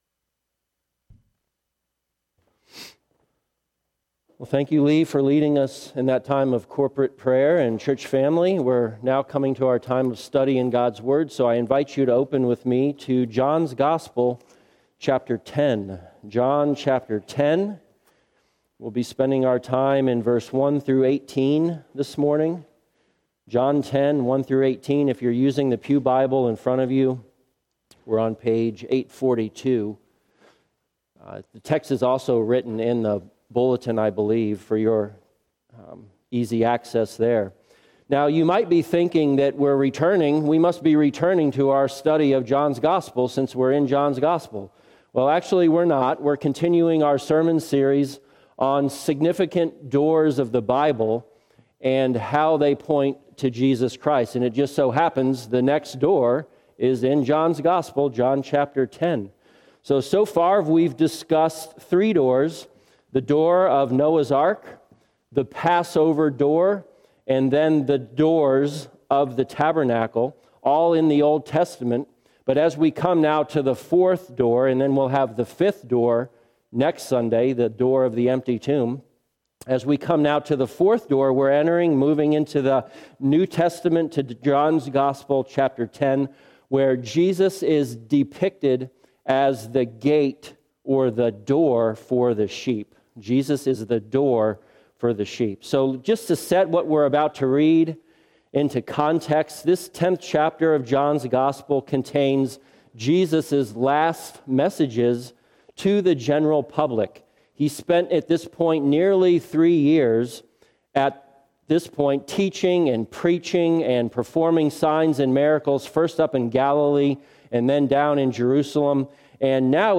Passage: John 10:1-18 Service Type: Sunday Morning